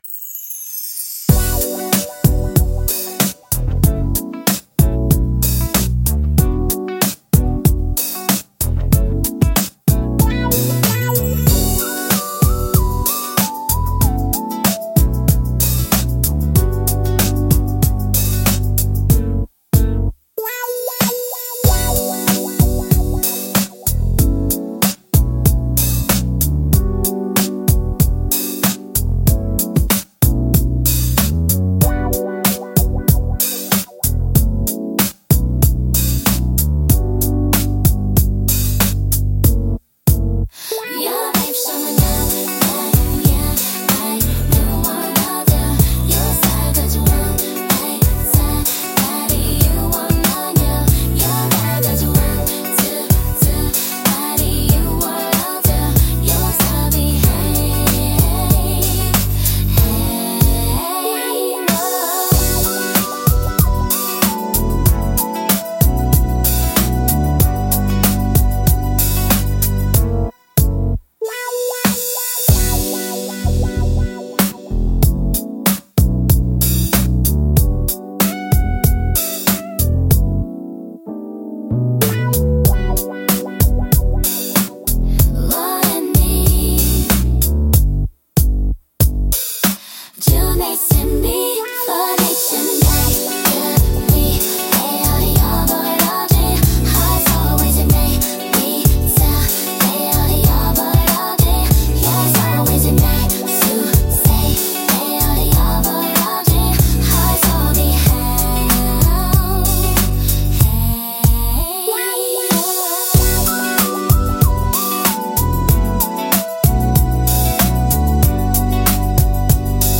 聴く人に心地よいリズム感と温かみを届ける、優雅で感性的なジャンルです。